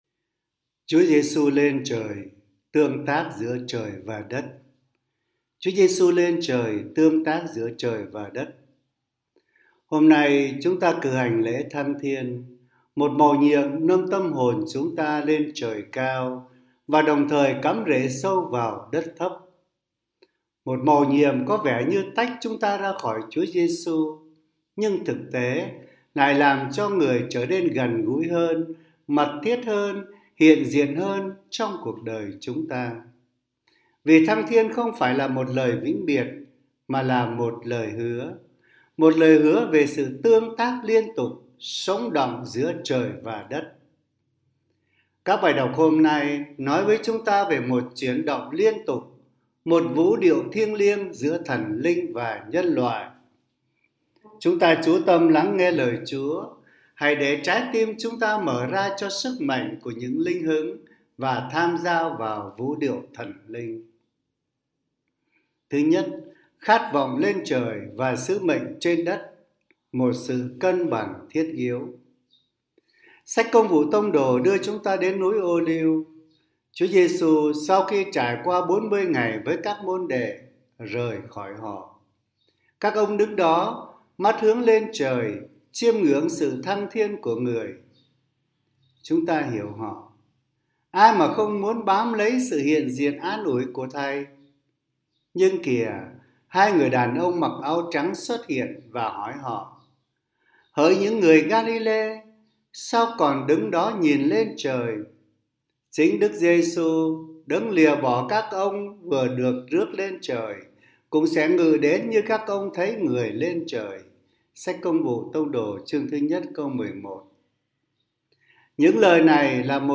Suy niệm Chúa Nhật